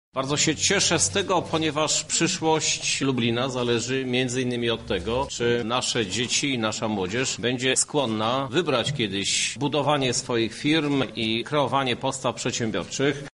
Podczas poniedziałkowej nietypowej konferencji prasowej 1 kwietnia wziął udział również prezydent Miasta Krzysztof Żuk. Jego zdaniem dzieci są zawsze ciekawym partnerem do dyskusji.
-mówi prezydent Żuk